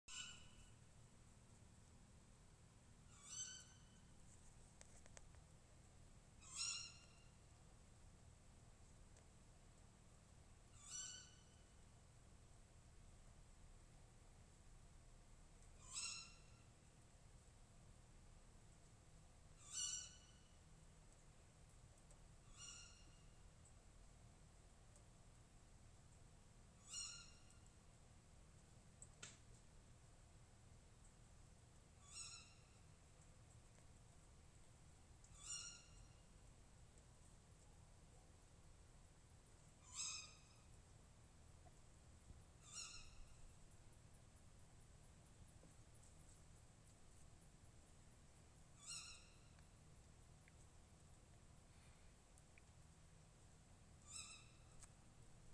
Putni -> Pūces ->
Meža pūce, Strix aluco
Piezīmes/vispirms dzirdēju, pēc tam arī ieraudzīju pārlidojam starp kokiem